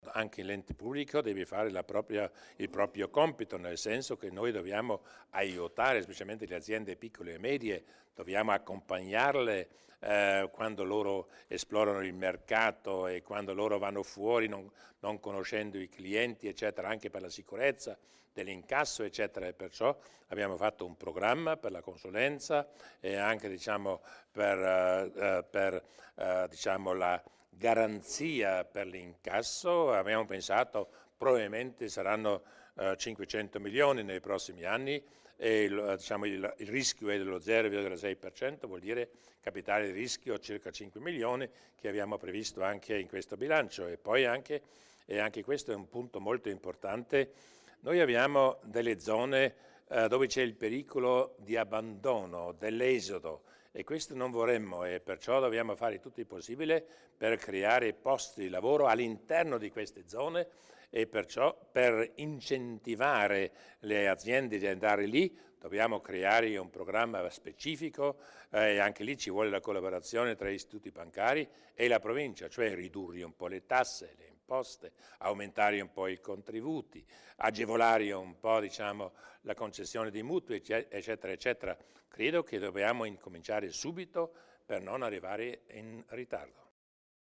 Incontro con le banche: il Presidente Durnwalder spiega i risultati più importanti